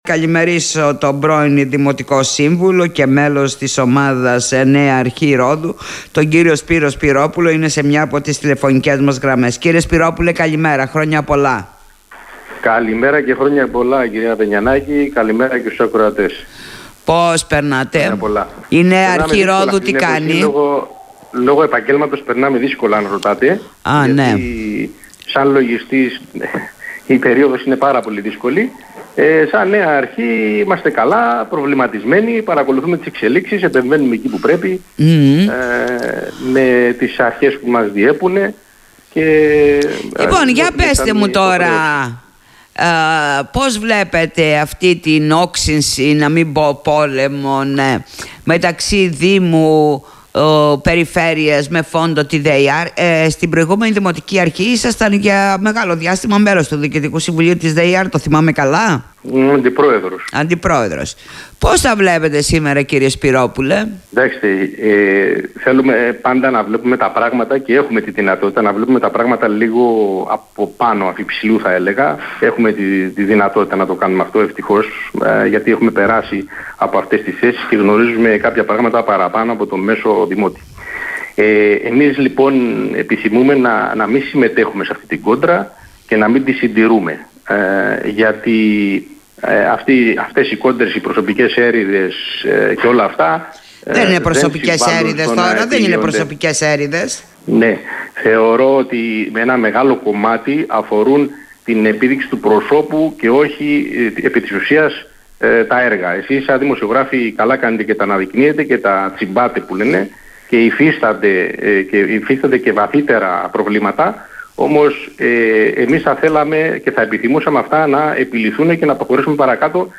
Ακούστε τον κ. Σπυρόπουλο: